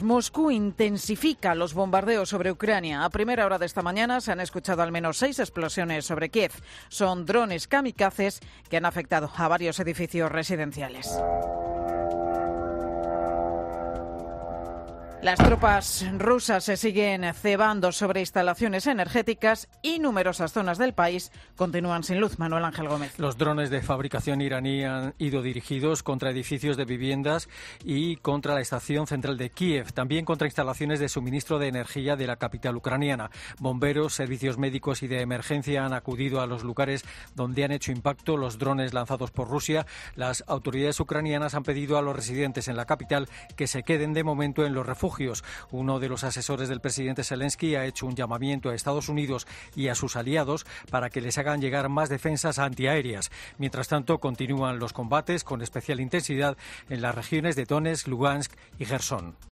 Rusia lanza ataques contra objetivos civiles y energéticos con drones kamikazes. Crónica